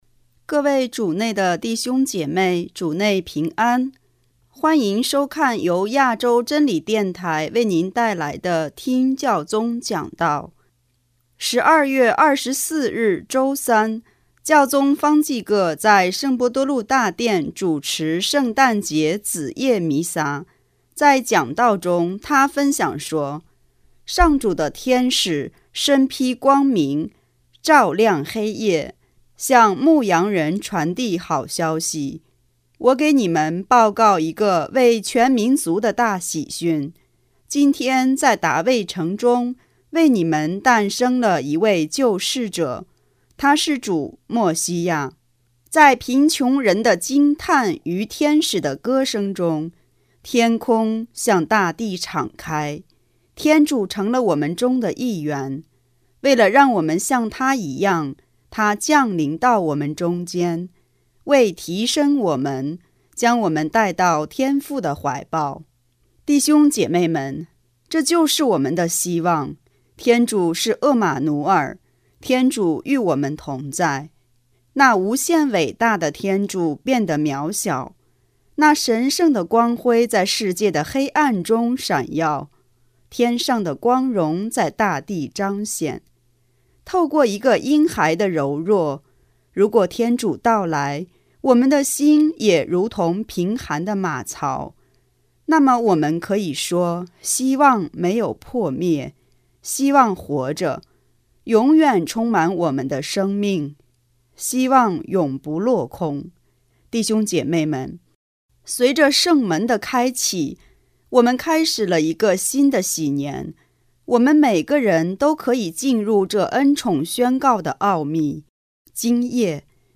【听教宗讲道】|希望永不落空
12月24日周三，教宗方济各在圣伯多禄大殿主持圣诞节子夜弥撒，在讲道中，他分享说：